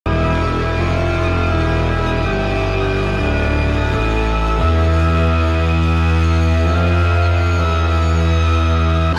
OminousElectronicPiece.mp3